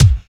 47 KICK.wav